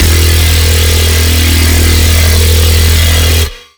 18656 Monsta F Stab 4s 0.08 MB